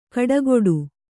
♪ kaḍagoḍu